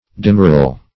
Demurral \De*mur"ral\, n.